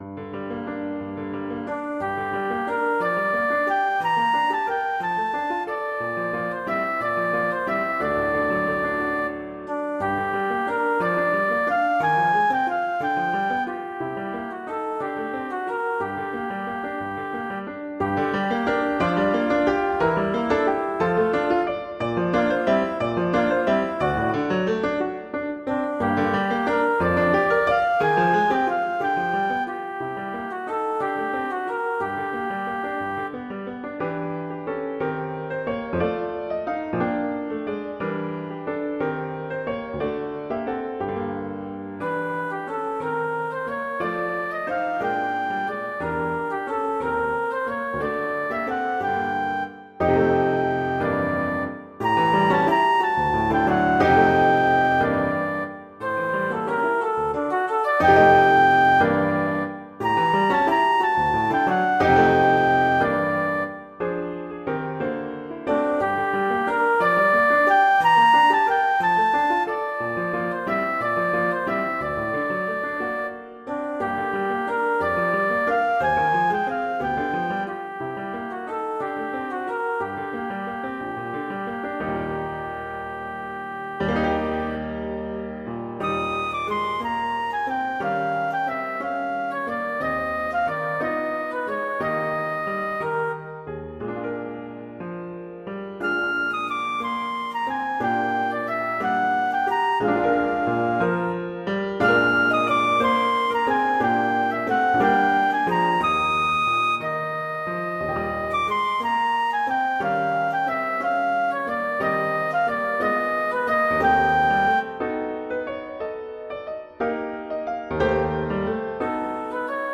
classical, french
G minor
♩. = 60 BPM